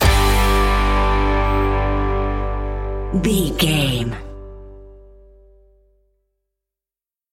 Ionian/Major
electric guitar
drums
bass guitar
Pop Country
country rock
happy
uplifting
driving
high energy